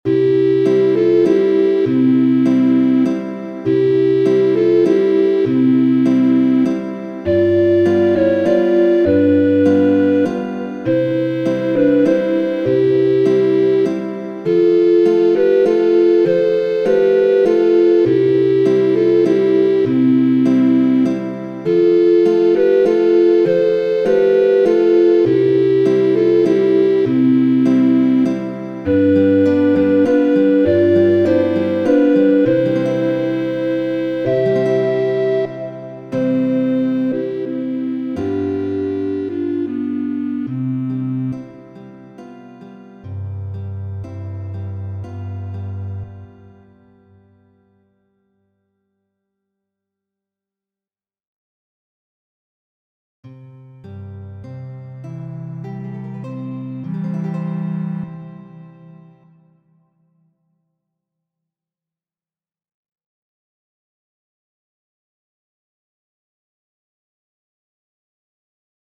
Kanto kaj akompano
Per ĉi tri akordoj ni povas akompani preskaŭ ĉiun kanton.